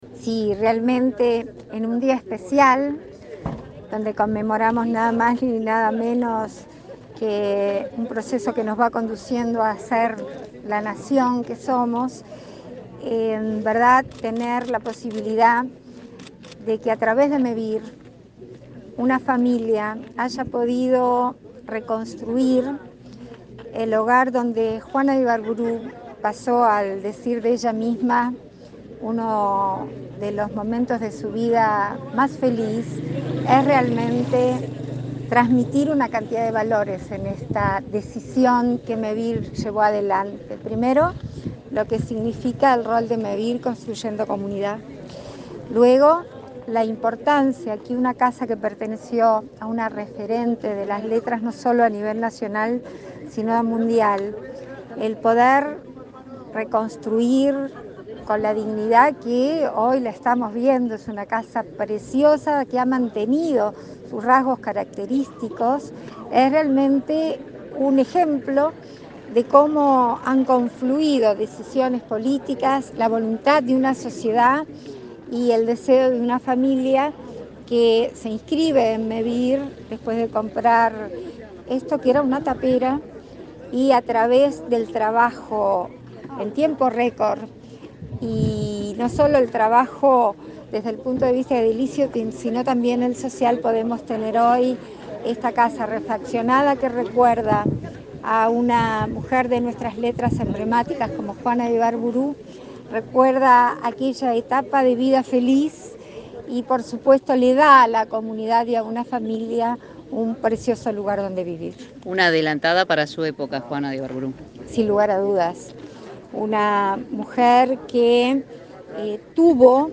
Entrevista a la vicepresidenta Beatriz Argimón
Entrevista a la vicepresidenta Beatriz Argimón 19/04/2022 Compartir Facebook X Copiar enlace WhatsApp LinkedIn La vicepresidenta Beatriz Argimón dialogó con Comunicación Presidencial luego de participar de la inauguración de una obra de reciclaje de Mevir, en la vivienda donde vivió la poetisa Juan de Ibarbourou en Santa Clara de Olimar, departamento de Treinta y Tres.